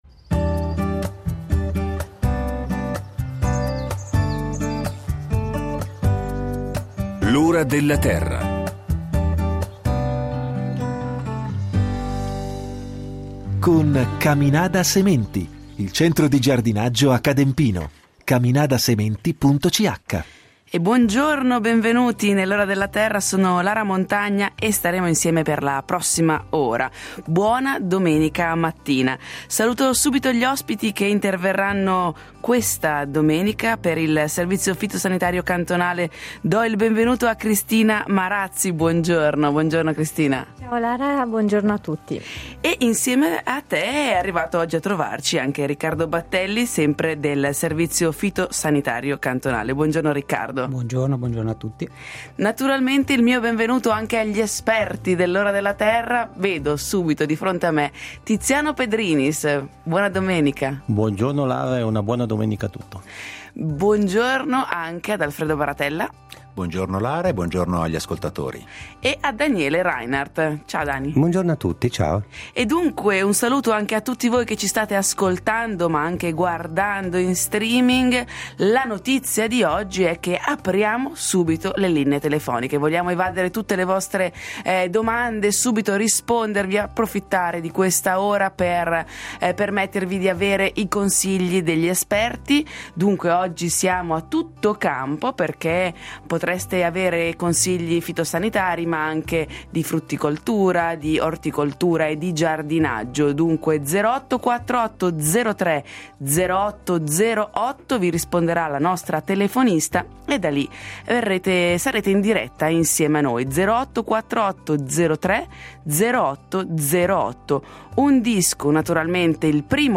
In studio con noi due rappresentanti del servizio fitosanitario cantonale.
Non mancano gli esperti del programma con le risposte agli ascoltatori.